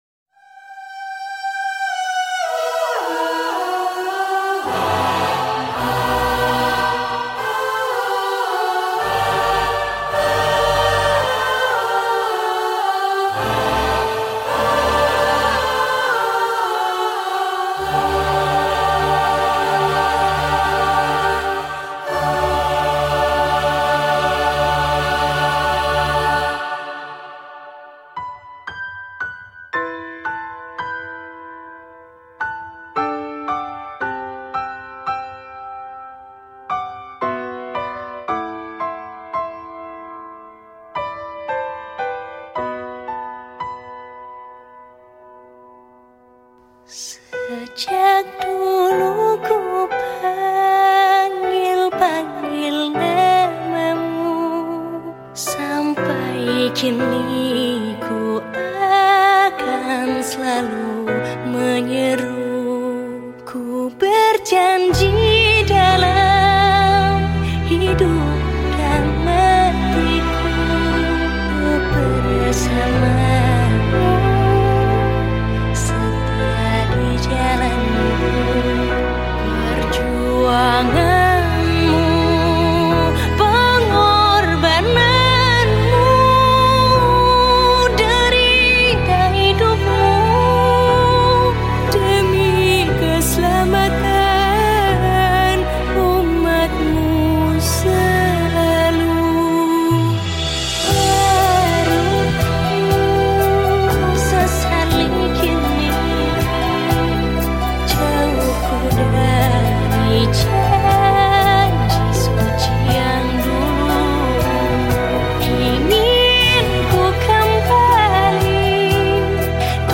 Genre Musik                              : Religi Sholawat